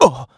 Riheet-Vox_Damage_kr_02.wav